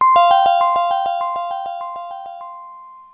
tinkle.wav